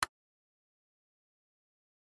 SFX_Click_Mechanical.mp3